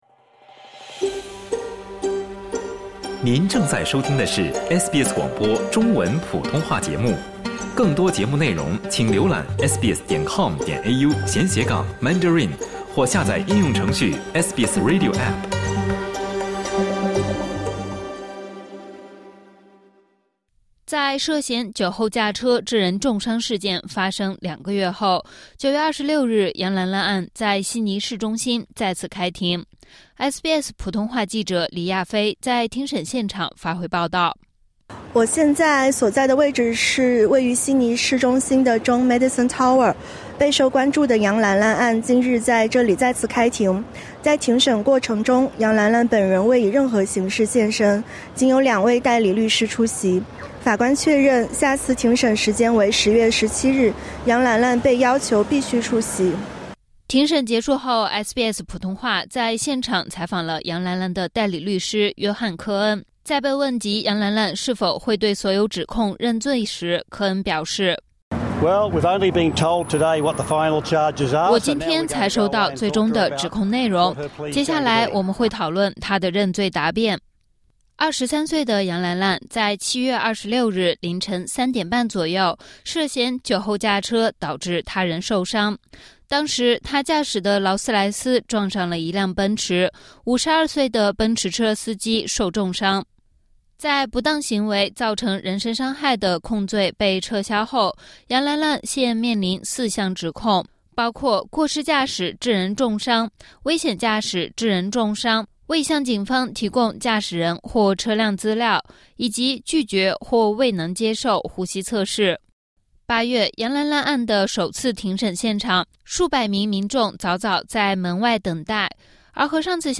（点击音频，收听详细报道）